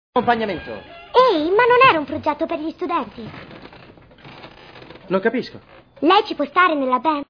voce di
nel film "School of Rock", in cui doppia Aleisha Allen.